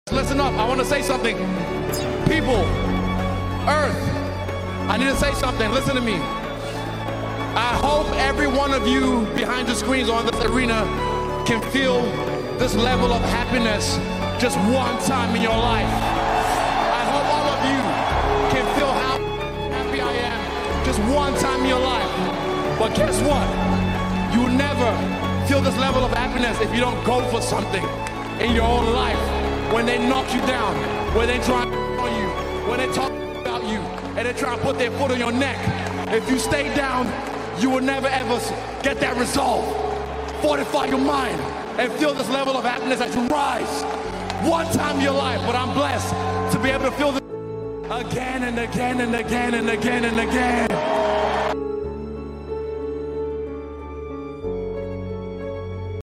Great post fight speech from Israel Adesanya